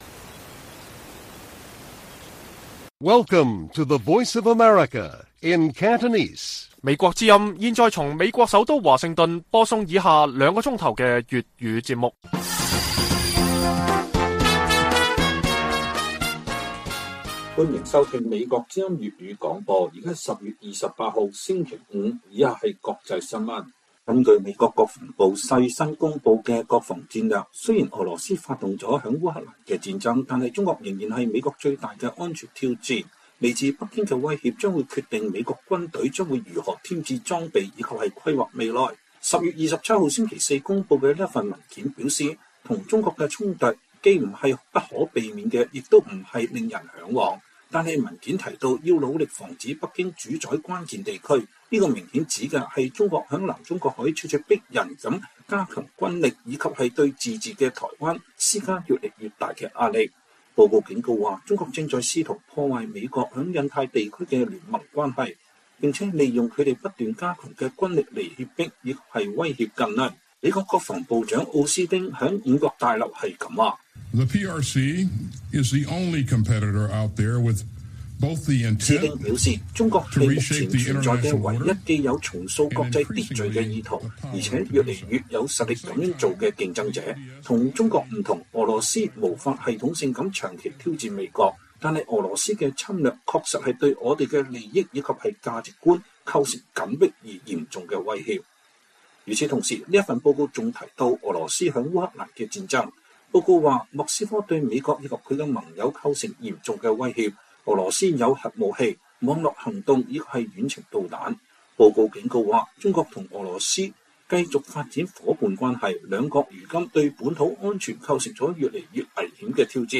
粵語新聞 晚上9-10點 : 中國加劇對台外交施壓 前美國際發展署官員：台灣應與民主盟友以合作品牌策略聯手援外